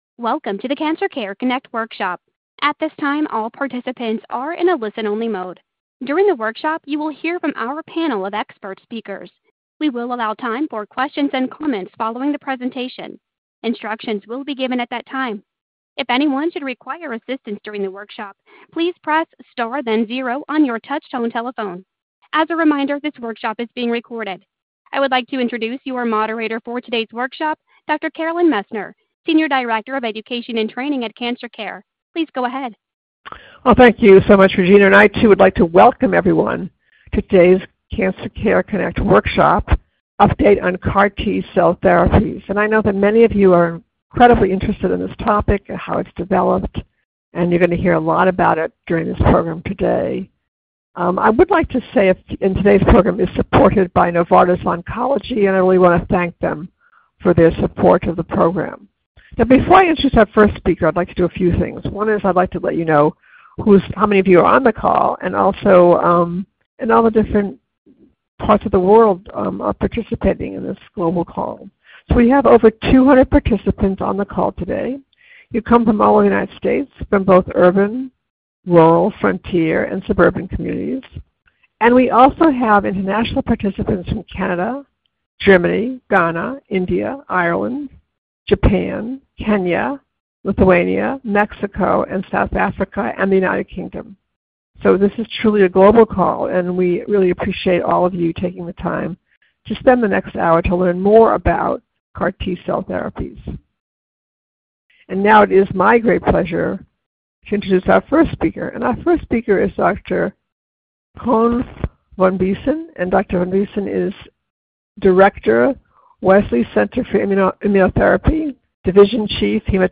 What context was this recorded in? This workshop was originally recorded on October 22, 2024.